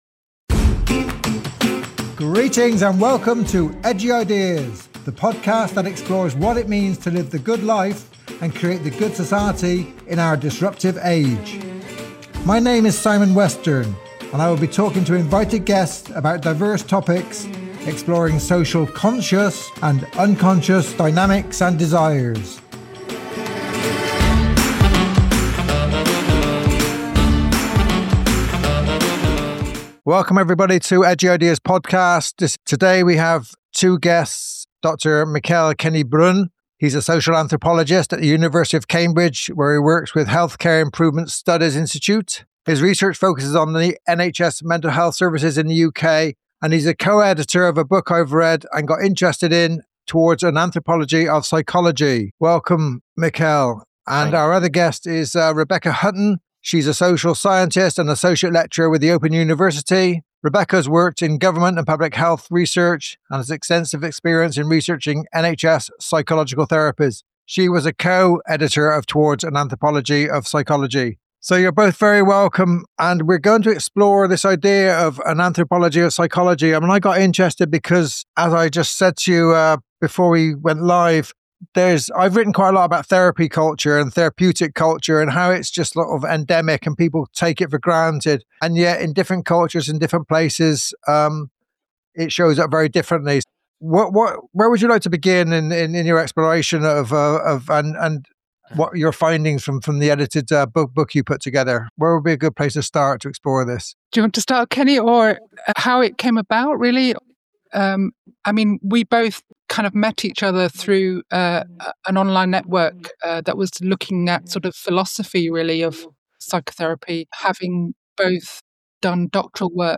Ethnography is central to this conversation, not just as a research method, but as a way of listening and staying with complexity.